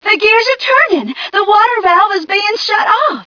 1 channel
mission_voice_m3ca023.wav